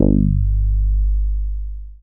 BASS03.WAV